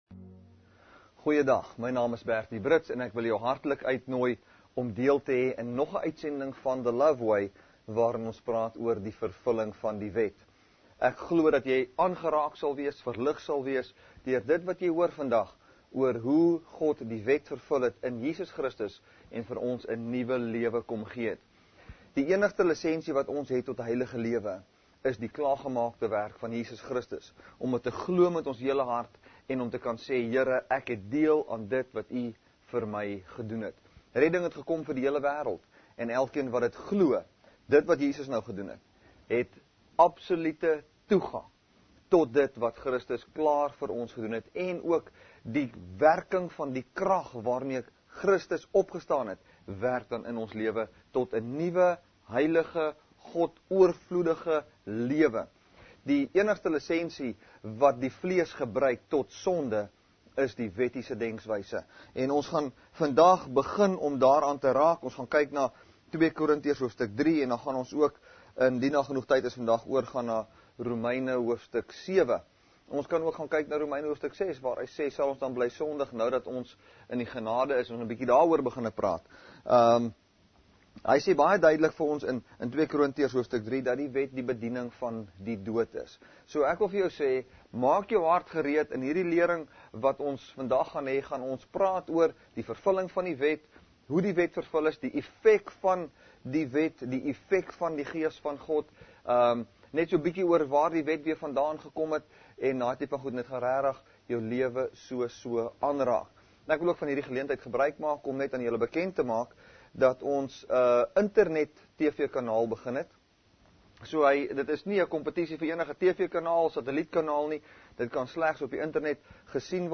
May 24, 2016 | TV BROADCASTING | Kruiskyk Uitsendings